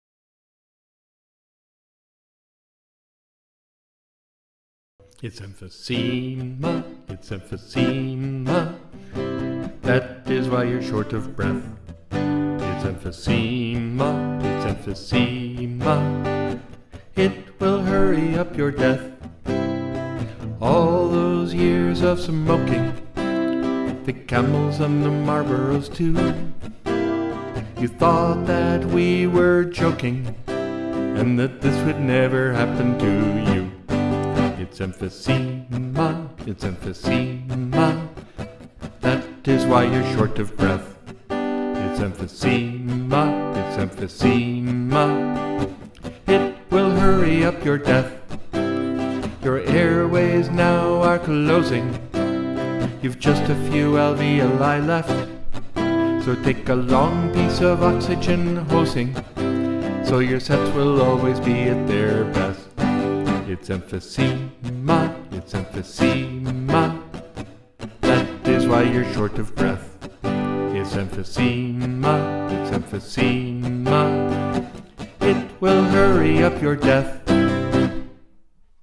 With a tune partially borrowed from